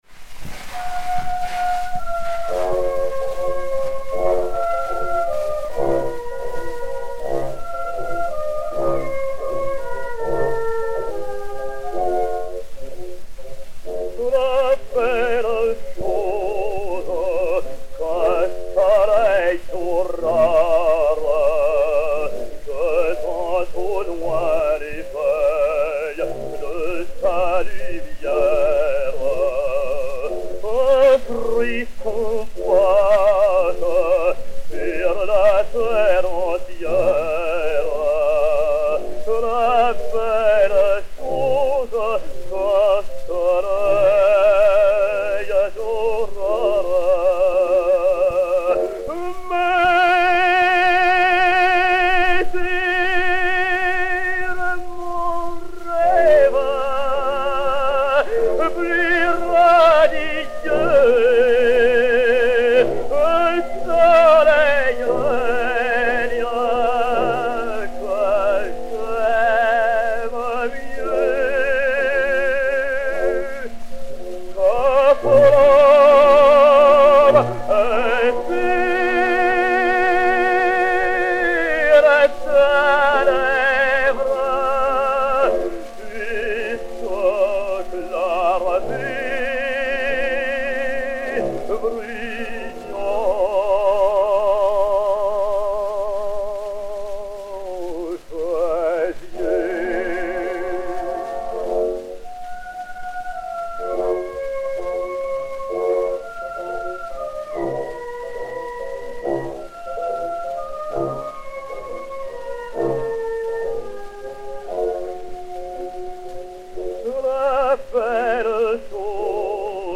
chanson napolitaine (Eduardo Di Capua) [version française]
Daniel Vigneau et Orchestre